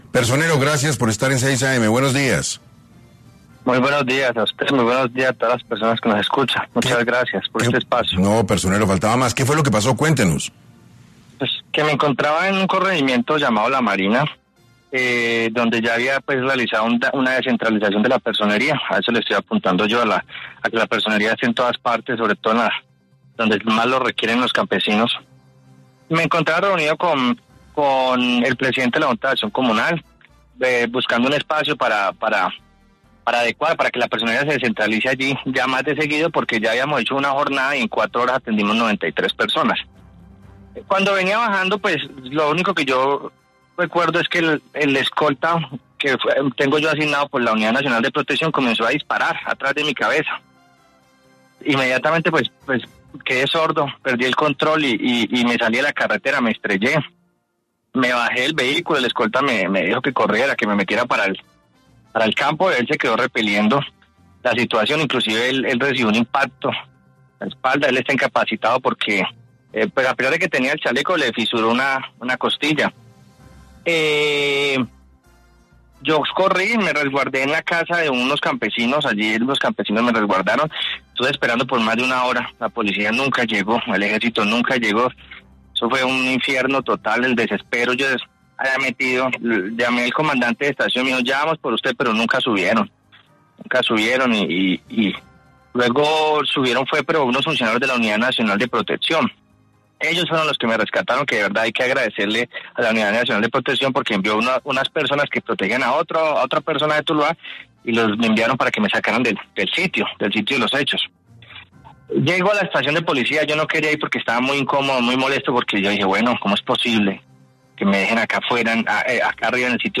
En 6AM de Caracol Radio estuvo Óscar Alejandro García, Personero de Tuluá, para hablar sobre un atentado que sufrió el pasado sábado 21 de septiembre y que casi le cuesta la vida.